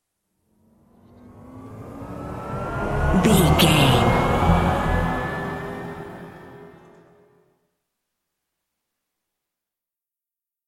Thriller
Aeolian/Minor
E♭
Slow
synthesiser
tension
ominous
dark
suspense
haunting
creepy
spooky